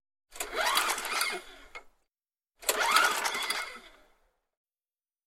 Шум двигателя газонокосилки